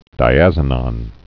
(dī-ăzə-nŏn)